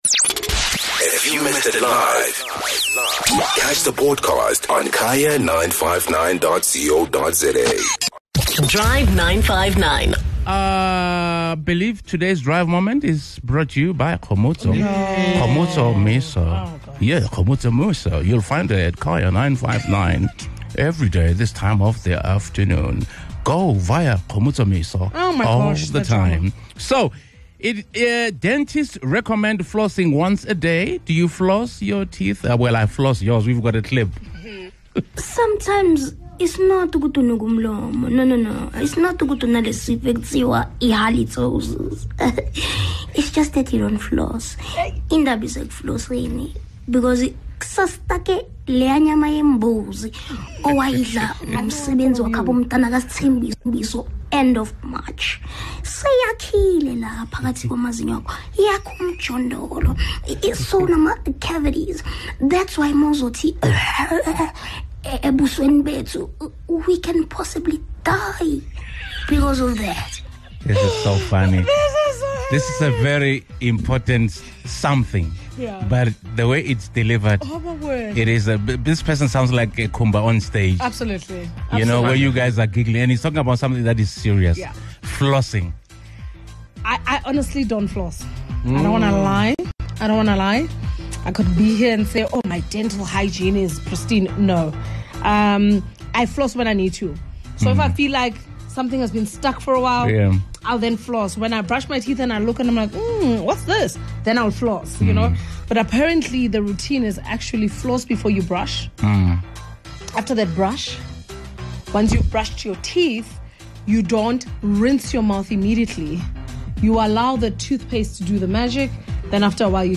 The Drive 959 Crew had fun debating about their daily oral hygiene habits, and hearing what listeners do in their routines too!